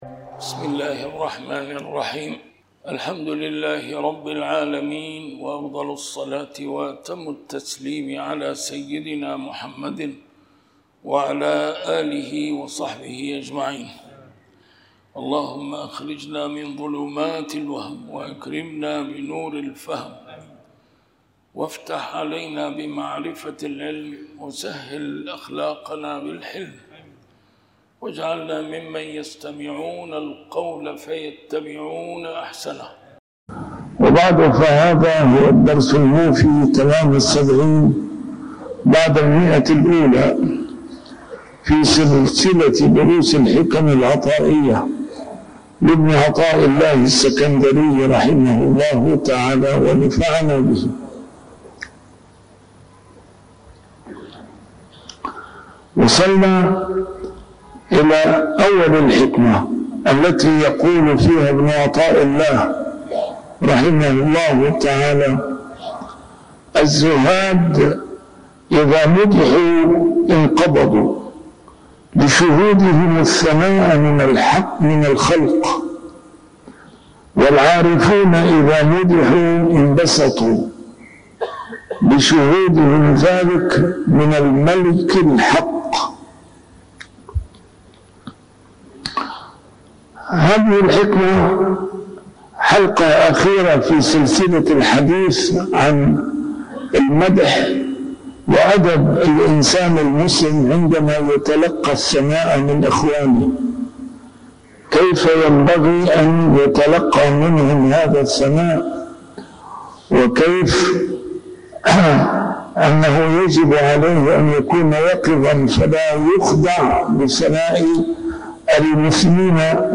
نسيم الشام › A MARTYR SCHOLAR: IMAM MUHAMMAD SAEED RAMADAN AL-BOUTI - الدروس العلمية - شرح الحكم العطائية - الدرس رقم 170 شرح الحكمة 146